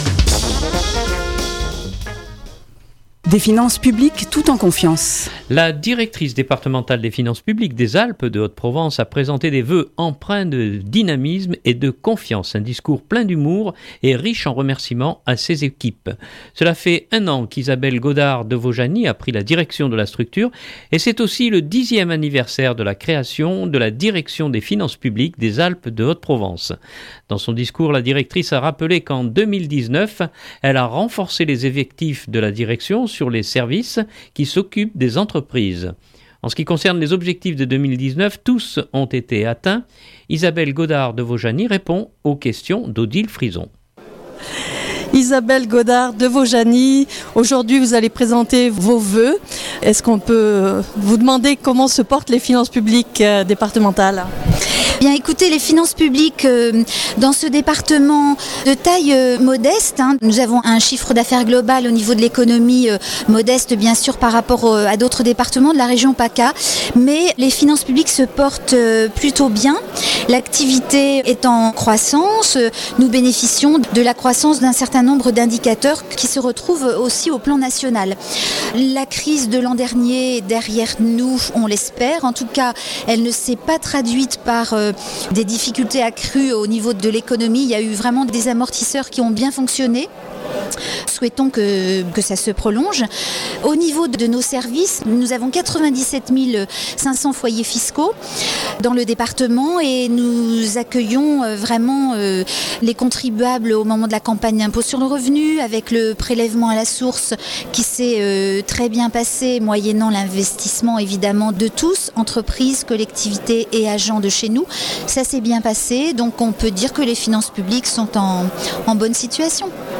Un discours plein d’humour, et riche en remerciement à ses équipes.